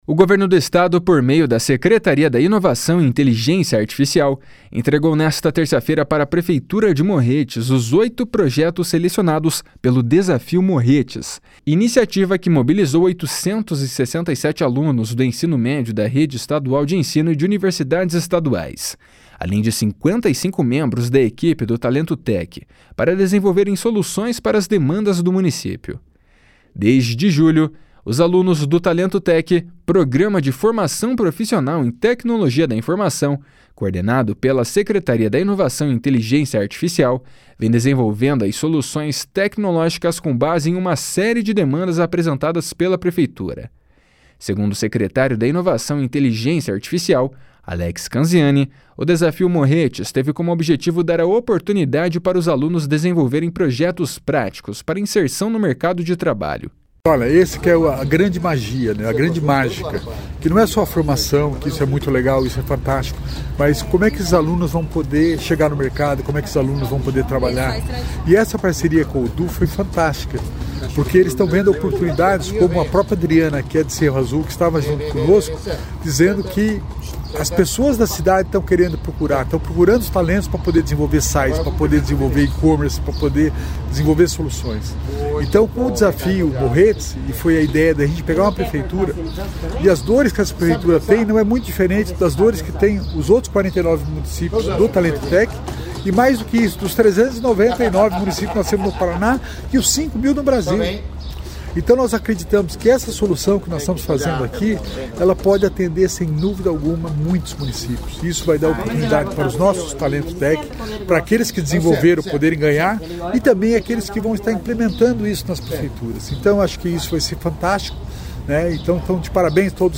// SONORA ALEX CANZIANI //